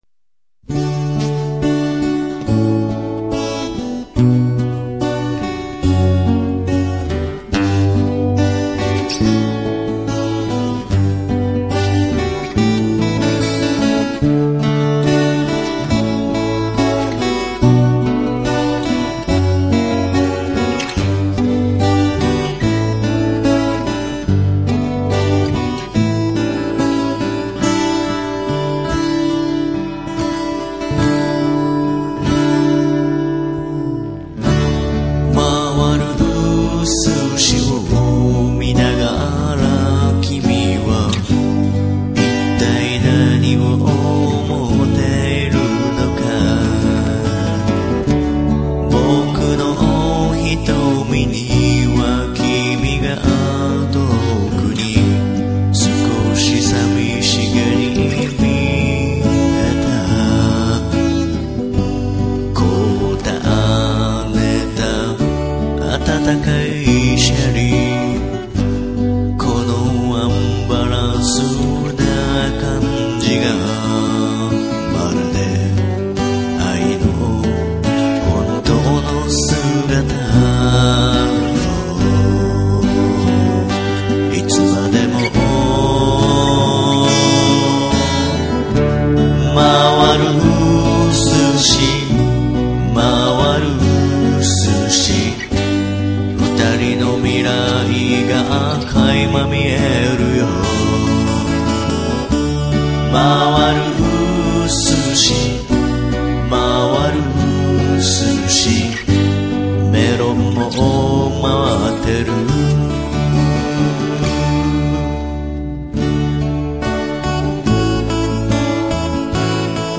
特に最後のコーラスは3声のハモリ＋Whooooのコーラスで厚みをつけています。
今回は、コーラスにこだわって作ってみました。
あえて、ギターのおかずにこってみました。